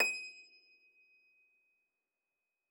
53j-pno23-D5.aif